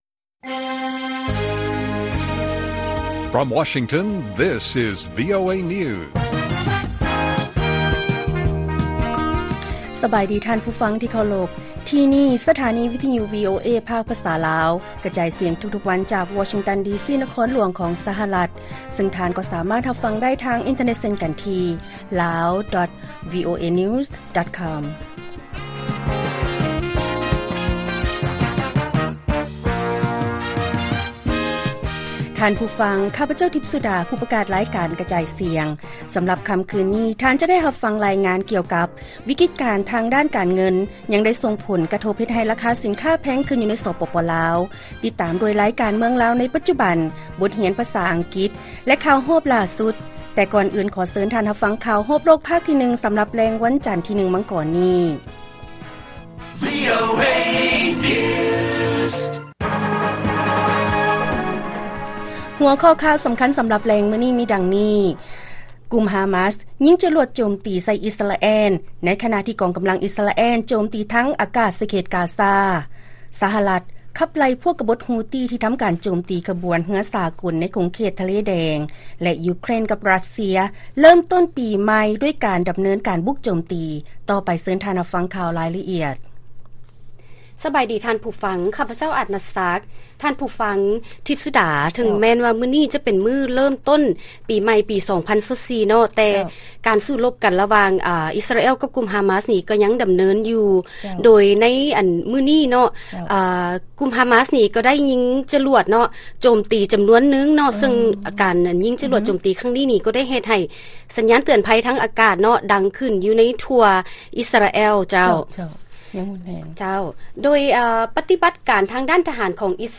ລາຍການກະຈາຍສຽງຂອງວີໂອເອ ລາວ: ກຸ່ມຮາມາສ ຍິງລູກຈະຫຼວດ ໂຈມຕີໃສ່ອິສຣາແອລ ໃນຂະນະທີ່ ກອງກຳລັງອິສຣາແອລ ໂຈມຕີທາງອາກາດ ໃສ່ເຂດກາຊາ